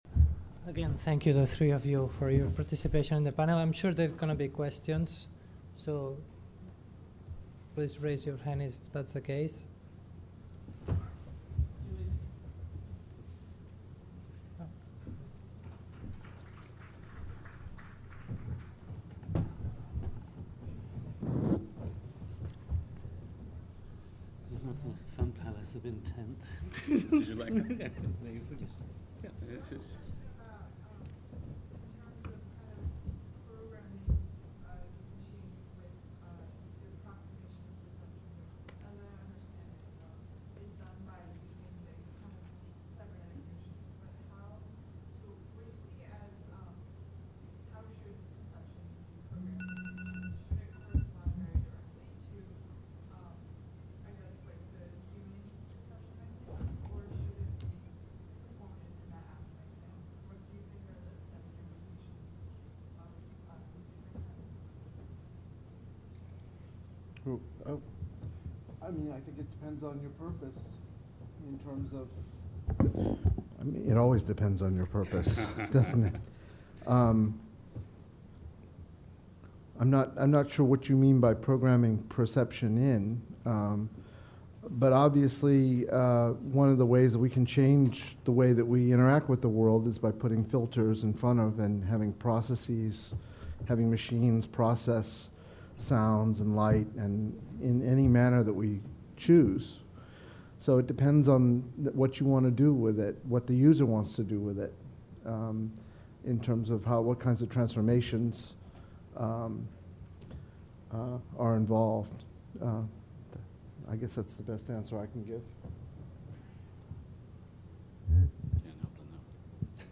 cybernetics-art-and-creativity-question-and-answer-session.mp3